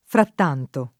frattanto [ fratt # nto ]